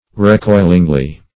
recoilingly - definition of recoilingly - synonyms, pronunciation, spelling from Free Dictionary Search Result for " recoilingly" : The Collaborative International Dictionary of English v.0.48: Recoilingly \Re*coil"ing*ly\, adv.
recoilingly.mp3